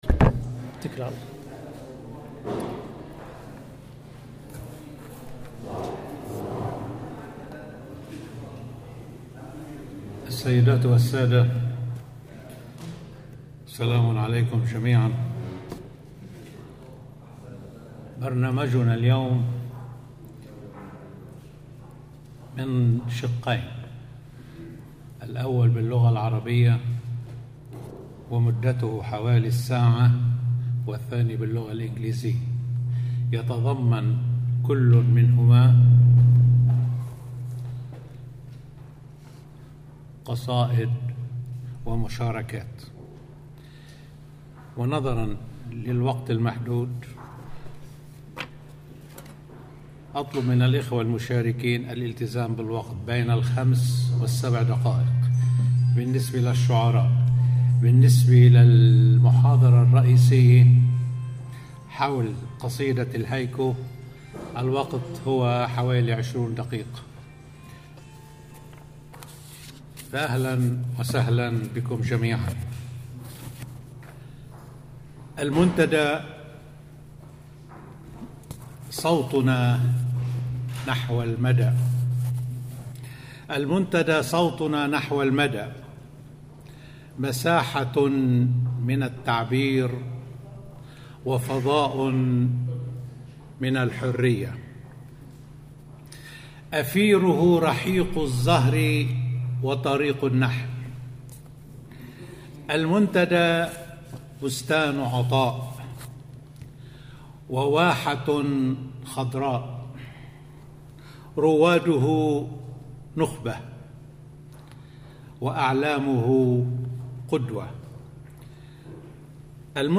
Arabic Poetry , Dearborn Open Mic